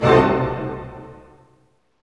Index of /90_sSampleCDs/Roland - String Master Series/HIT_Dynamic Orch/HIT_Orch Hit dim
HIT ORCHD03R.wav